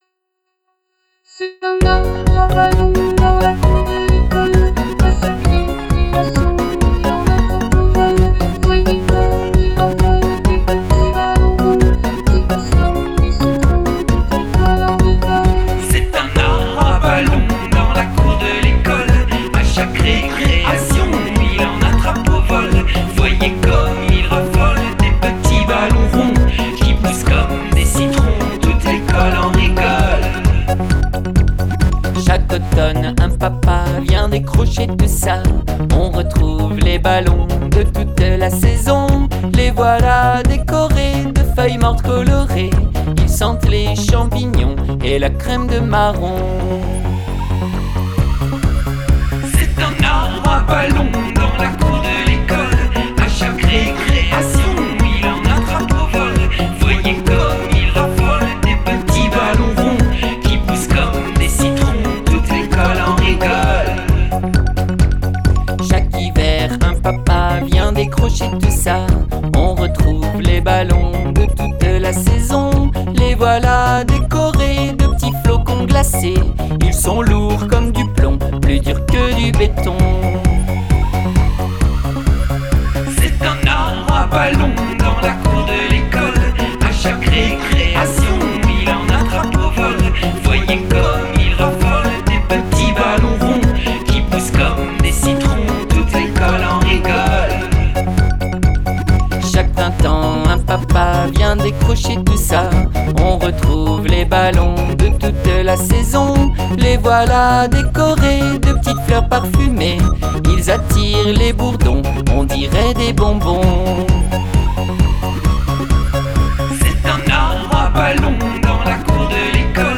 Chanson pour enfants (petits et grands)